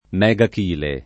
megachile [ m Hg ak & le ] s. m. (zool.)